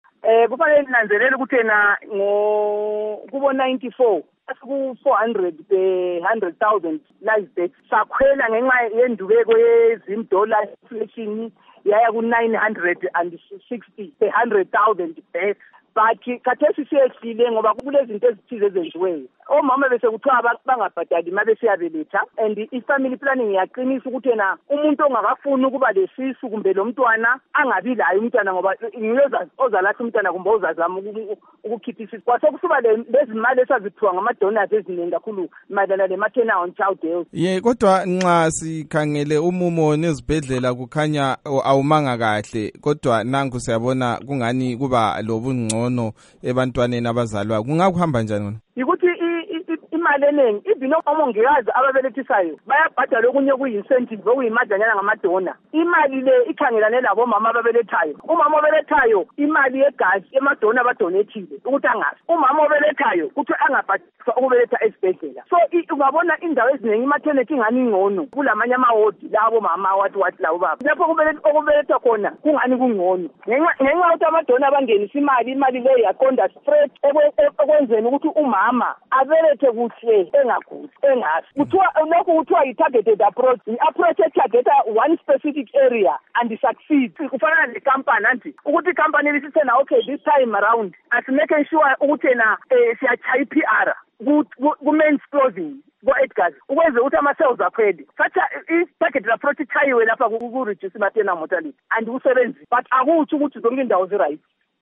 Ingxoxo loNkosikazi Ruth Labode